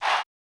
LEX Chant.wav